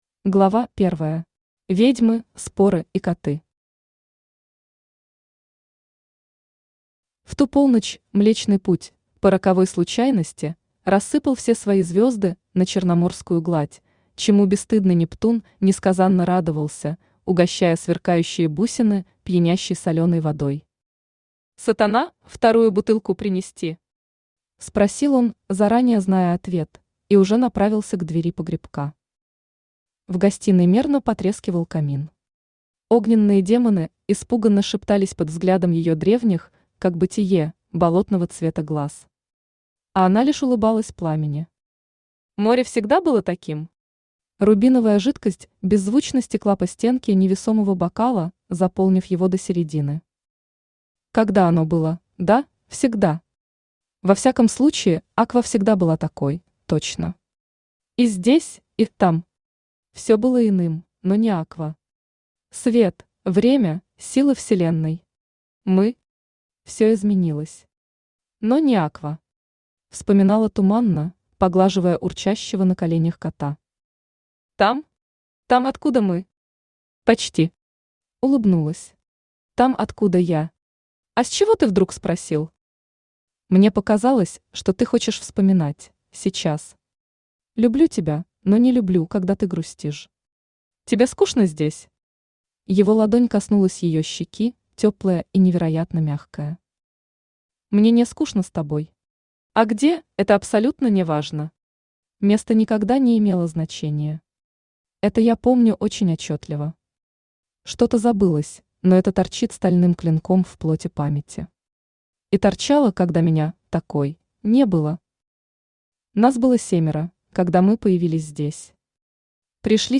Аудиокнига Осколки Иных Миров. Начало | Библиотека аудиокниг
Начало Автор Erida Shine Читает аудиокнигу Авточтец ЛитРес.